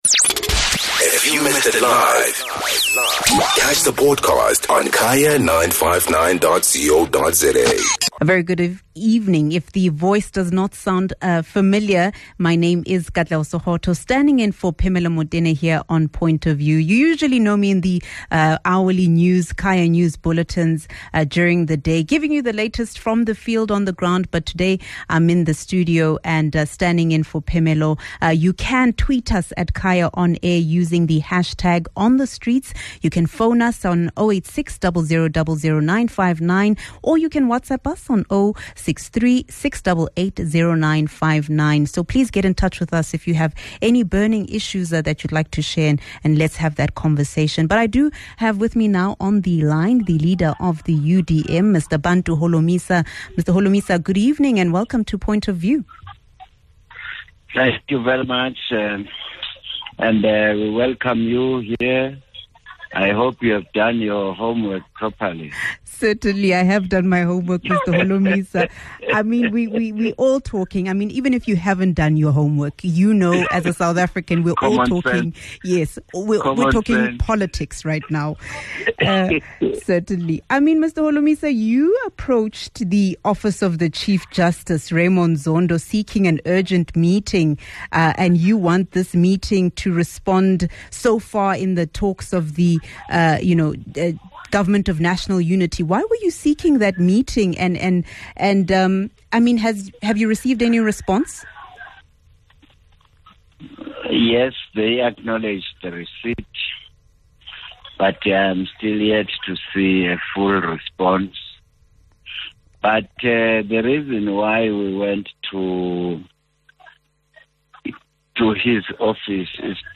Guest: Bantu Holomisa - UDM Leader